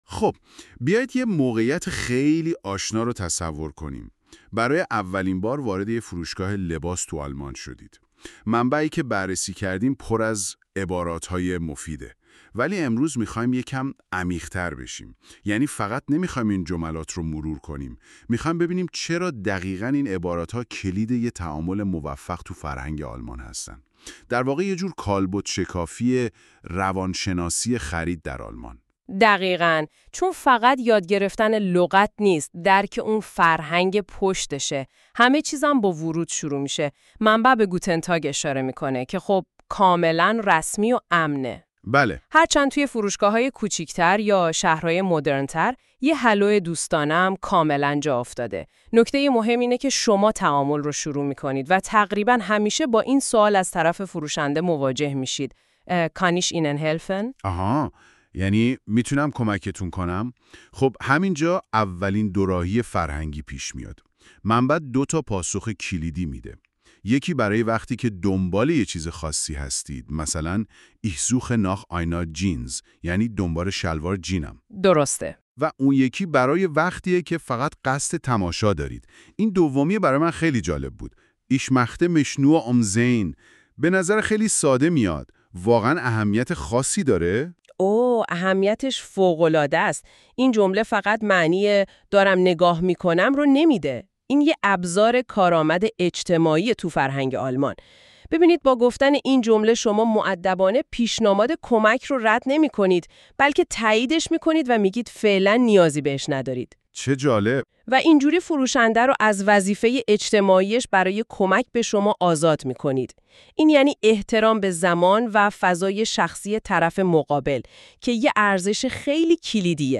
german-conversation-in-a-store.mp3